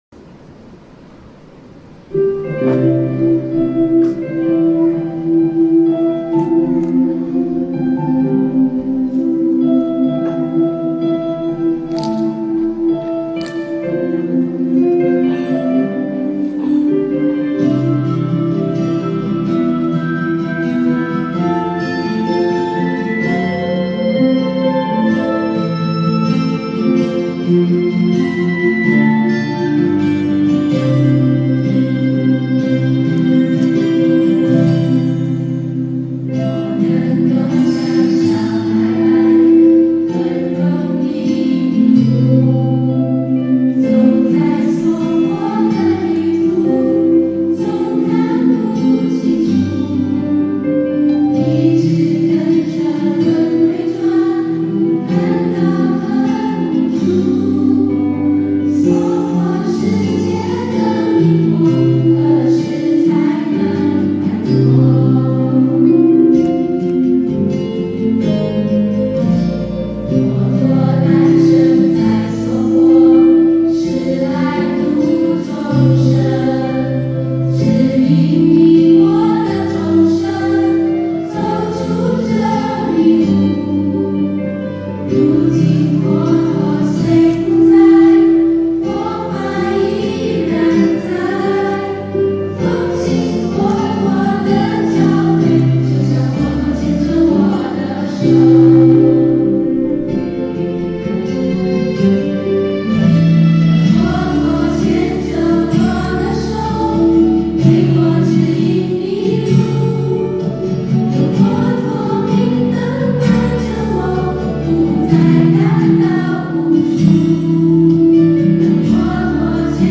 音频：新加坡佛友合唱《佛陀牵着我的手》一曲一天堂、一素一菩提！